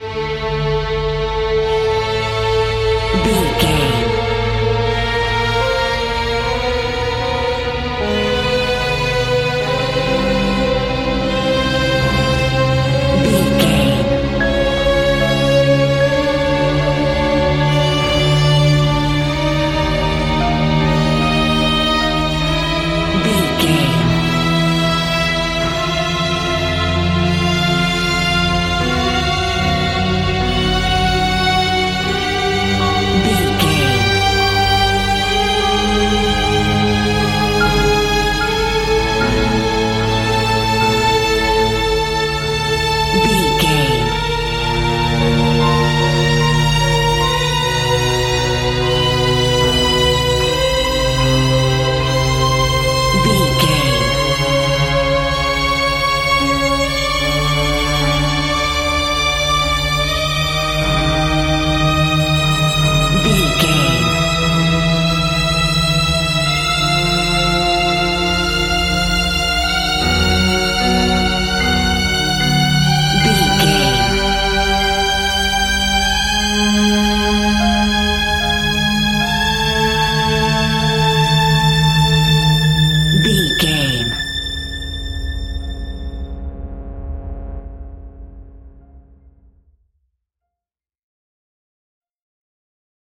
In-crescendo
Atonal
ominous
dark
suspense
eerie
strings
piano
synth
ambience
pads